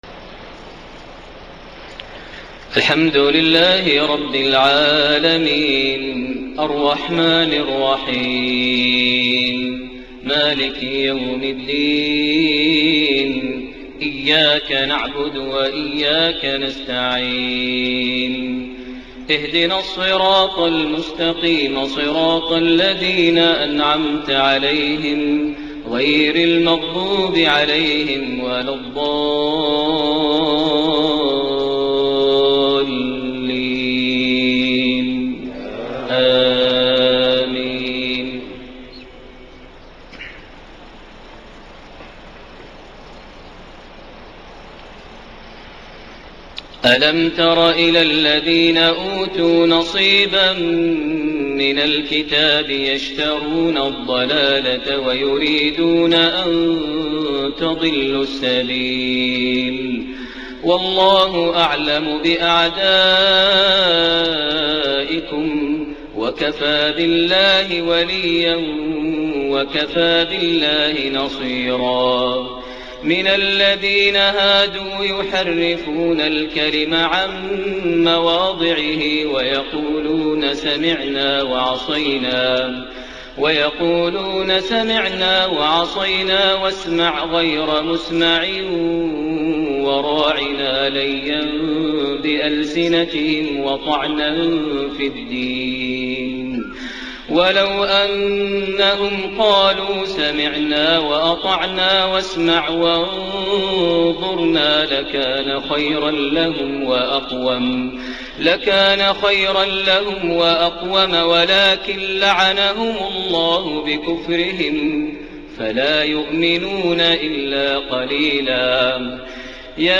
صلاة الفجر 6 شعبان 1433هـ من سورة النساء 44-57 > 1433 هـ > الفروض - تلاوات ماهر المعيقلي